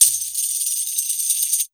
152JAMTAMB-R.wav